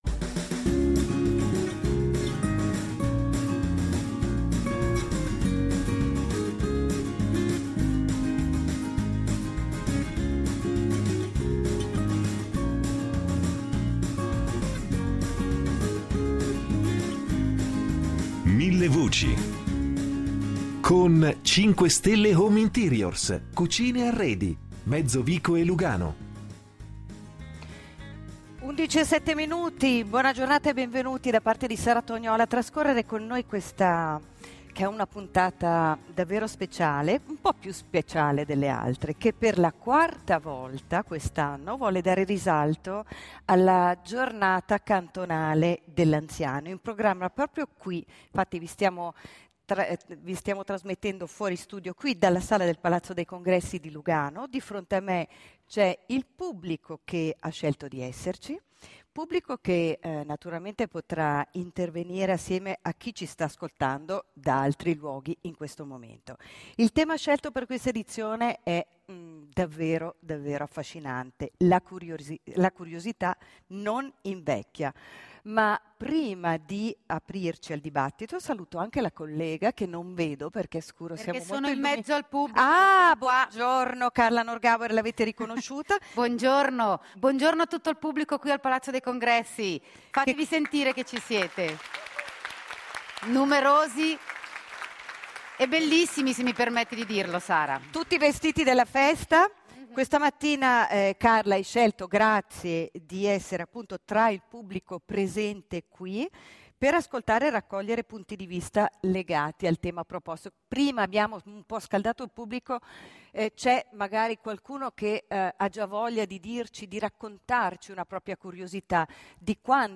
in diretta dal Palazzo dei Congressi di Lugano